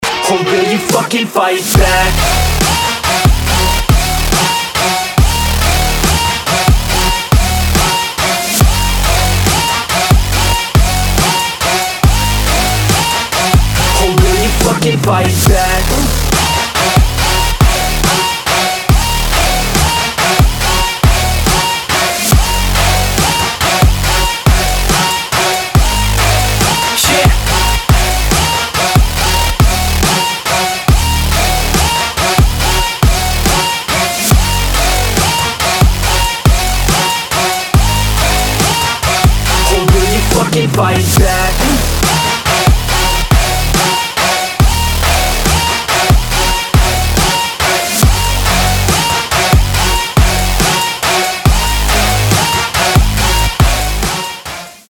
• Качество: 320, Stereo
мужской голос
ритмичные
Electronic
мощные басы
Trap
качающие
Bass
Качающий рингтон.